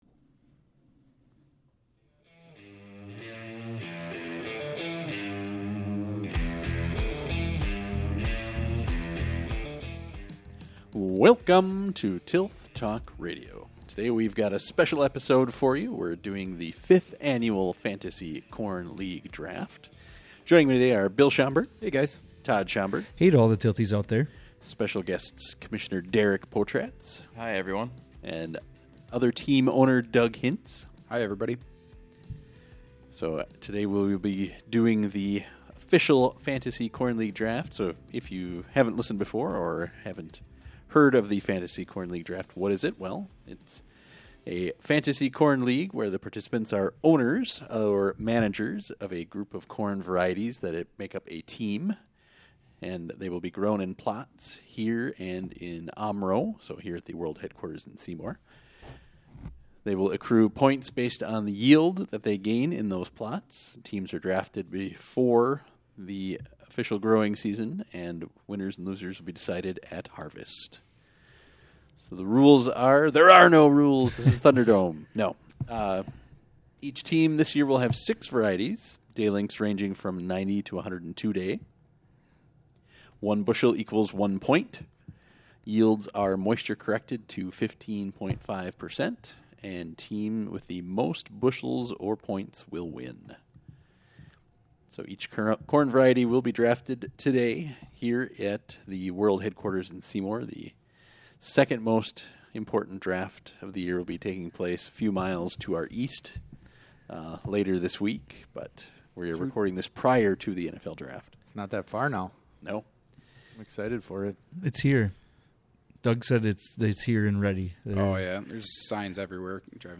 Special Interview Episode